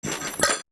UI_SFX_Pack_61_24.wav